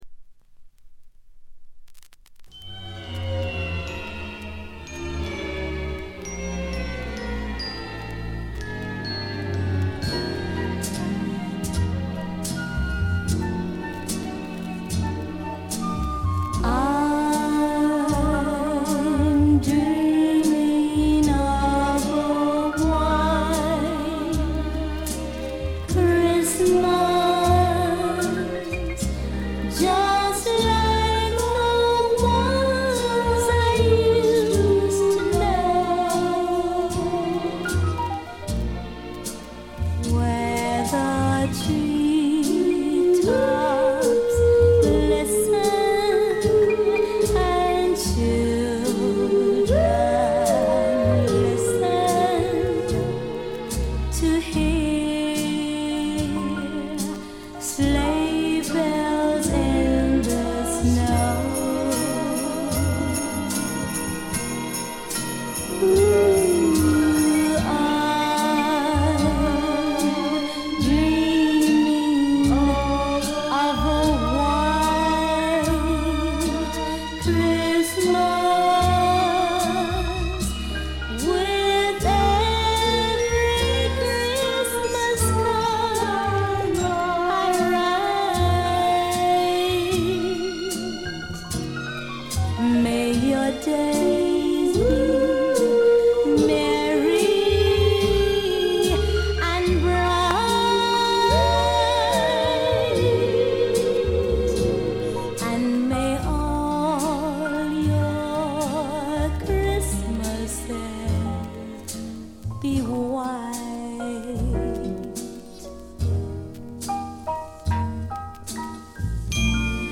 ガールポップ基本。
試聴曲は現品からの取り込み音源です。